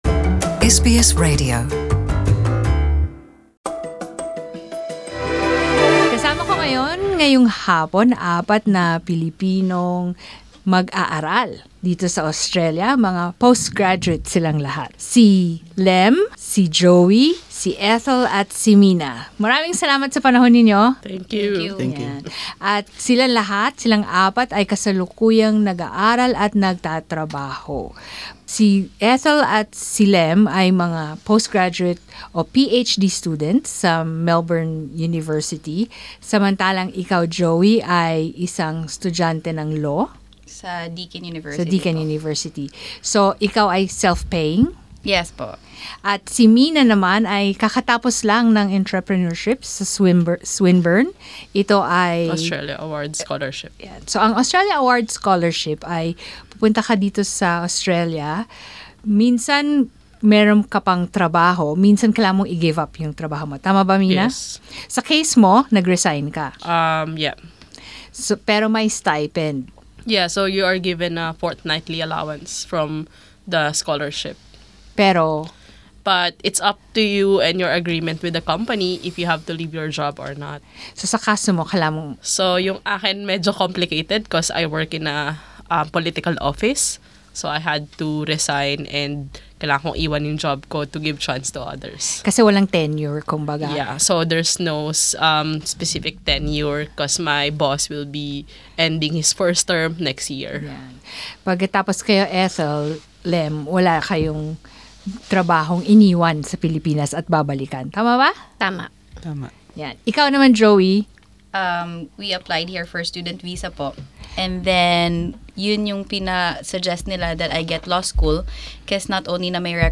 In this podcast, we speak to four Filipino international students about:
at SBS studio in Melbourne